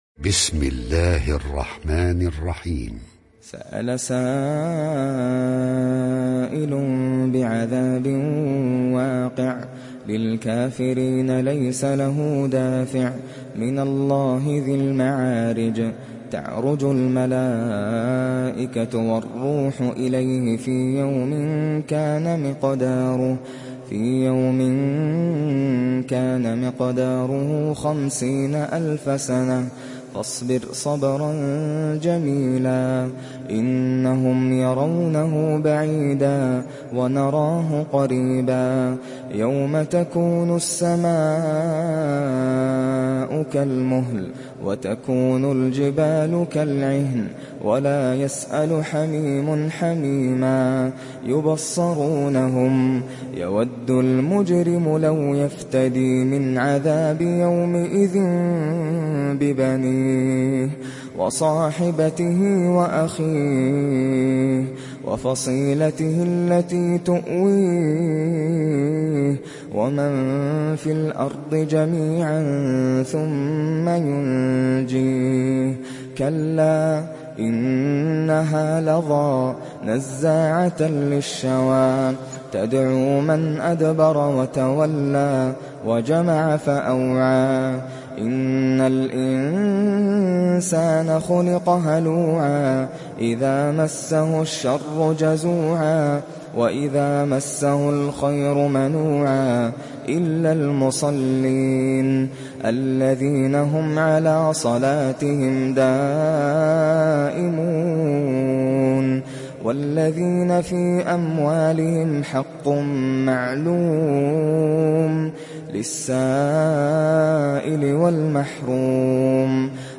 تحميل سورة المعارج mp3 بصوت ناصر القطامي برواية حفص عن عاصم, تحميل استماع القرآن الكريم على الجوال mp3 كاملا بروابط مباشرة وسريعة